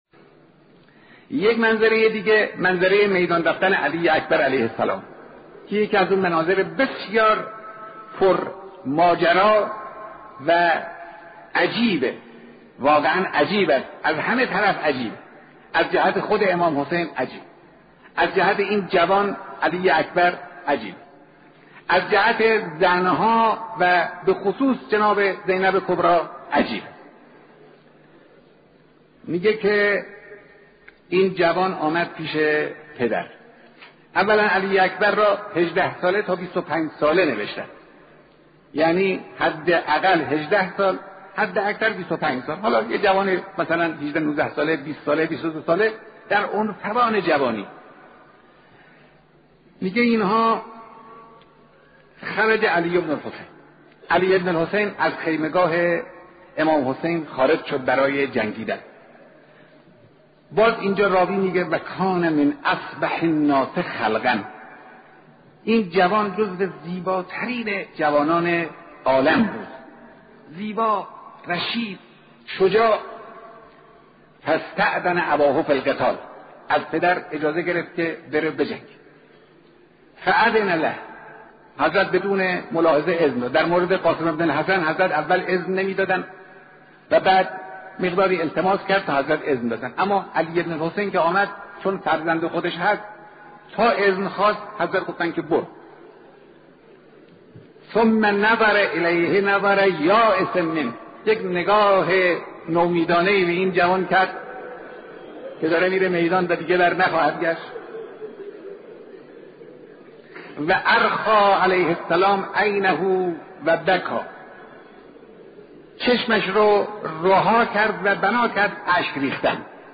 صوت کامل بیانات
روضه‌خوانی حضرت علی‌اکبر علیه‌السلام توسط رهبر انقلاب در نماز جمعه